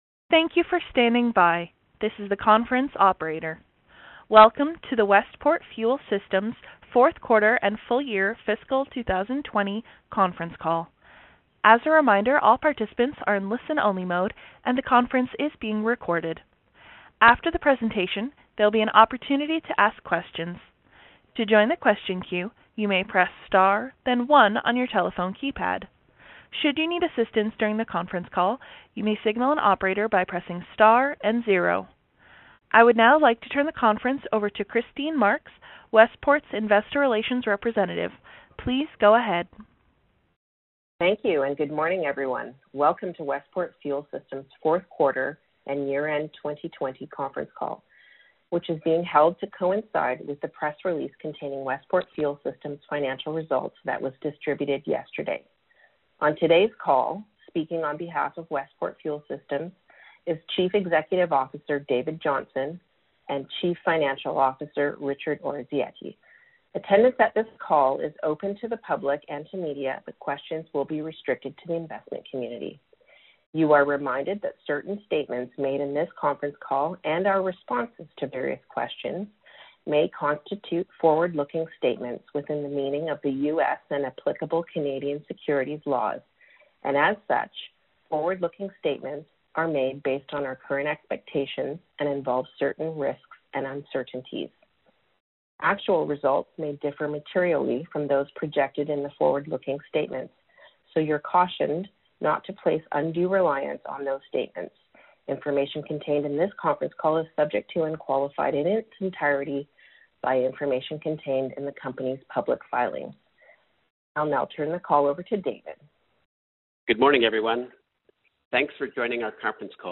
Westport Fuel Systems Inc (TSX: WPRT) Q4 2020 Earnings Conference
Watch or listen to the earnings call recording.